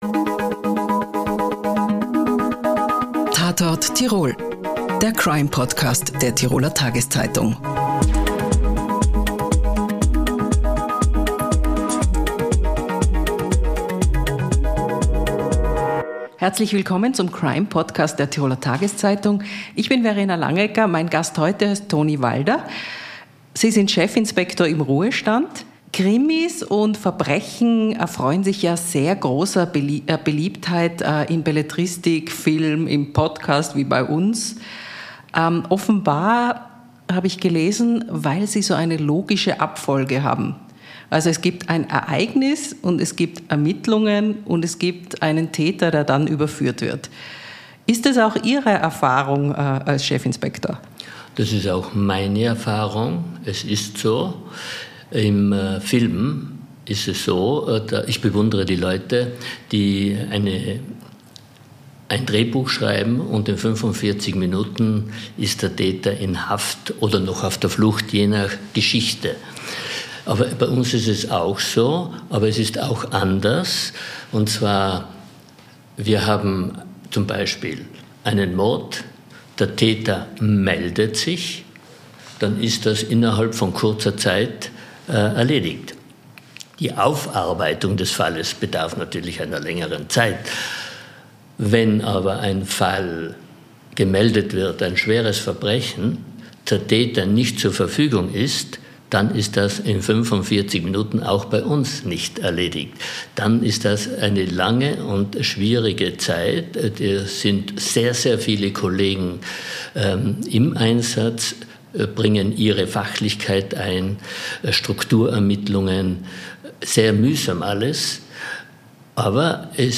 „Tatort Tirol“ ist der Crime-Podcast der Tiroler Tageszeitung. In dieser fünfteiligen Serie bitten wir Menschen zum Gespräch, die beruflich mit Verbrechen zu tun haben.